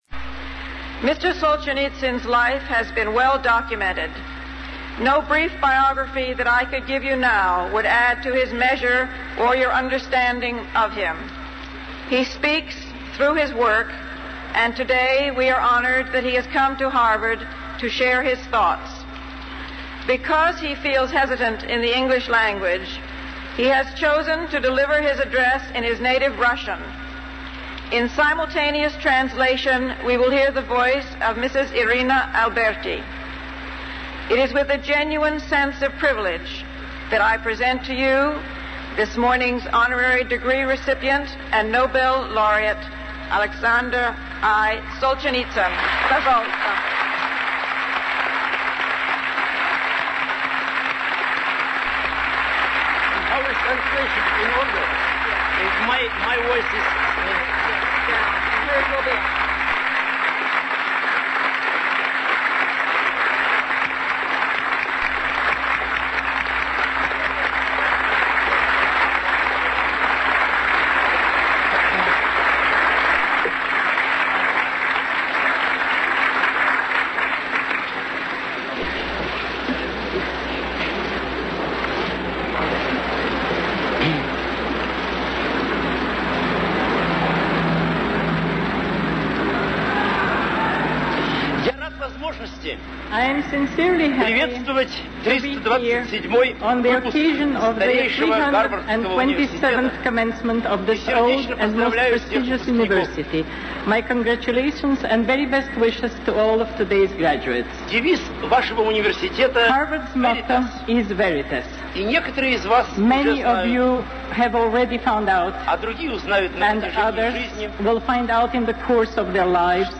Solzhentitsyn's "A World Split Apart" 1978 Harvard Commencement Address - LIT2120 World Literature II OER - LibGuides at Indian River State College
alexandersolzhenitsynharvard.mp3